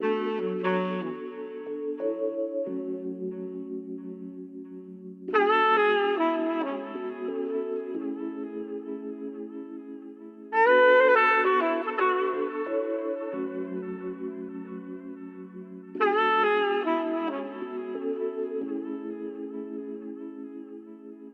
horns1